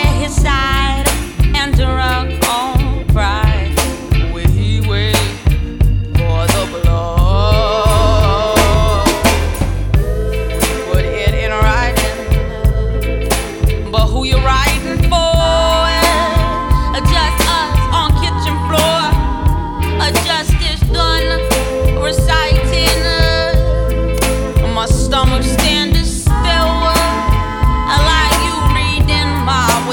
Плавные вокальные партии и чувственный бит
Глубокий соул-вокал и плотные хоровые партии
Жанр: R&B / Соул